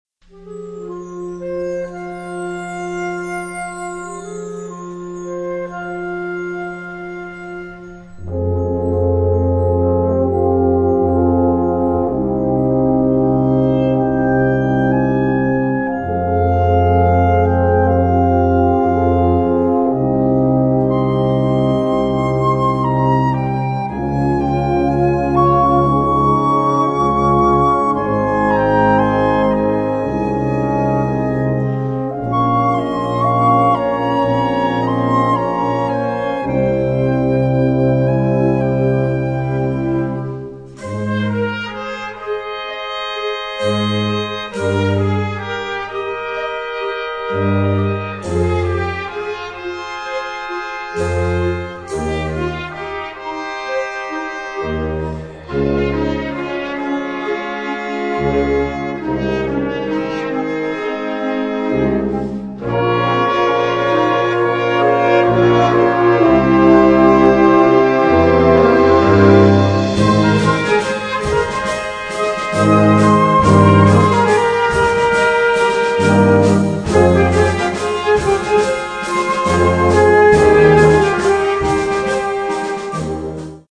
Catégorie Harmonie/Fanfare/Brass-band